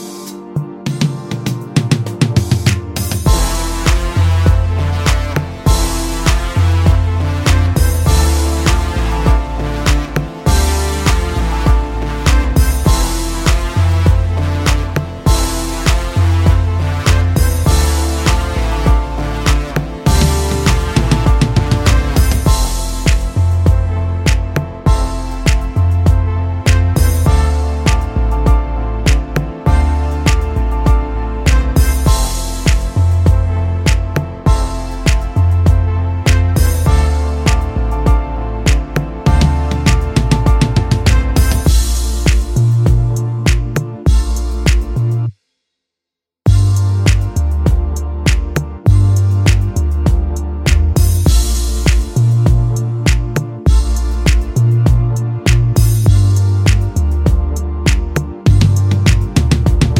no Backing Vocals Pop (2010s) 3:27 Buy £1.50